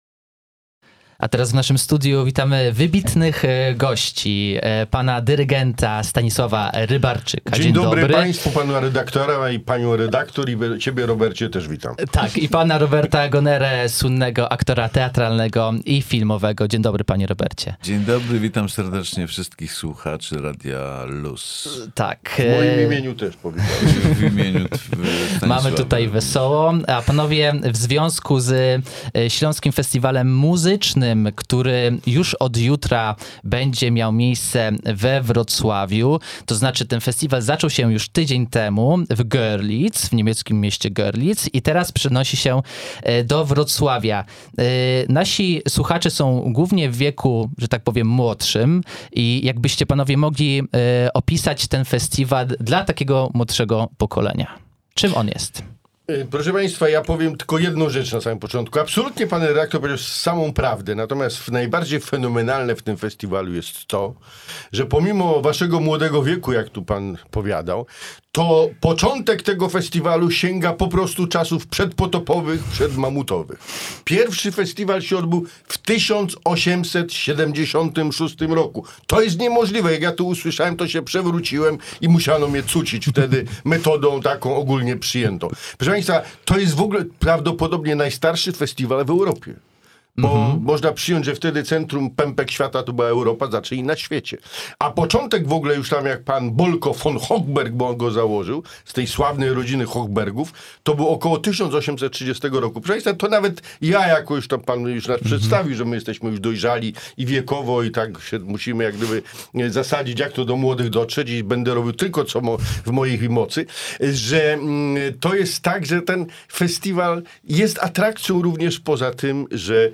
O roli sztuki w procesie przemiany, w piątkowej Pełnej Kulturze, rozmawiają